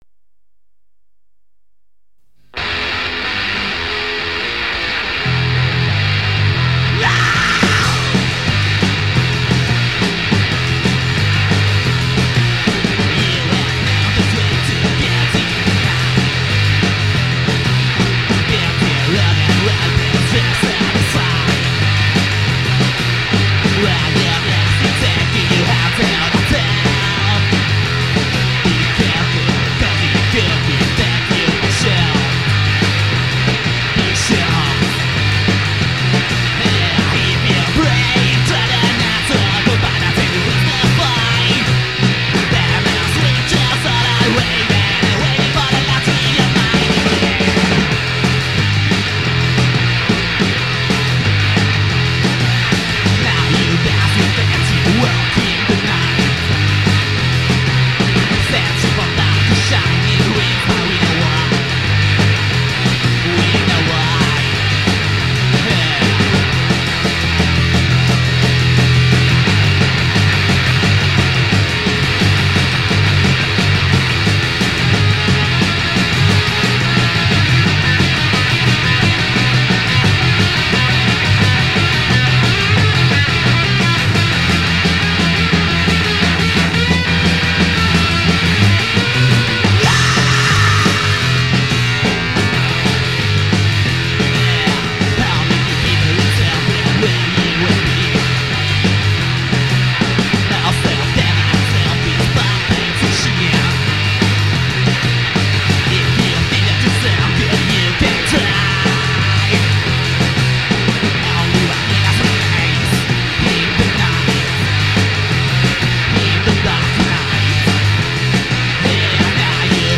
Basso
Batteria & cori
Voce e tambourine
Chitarra fuzz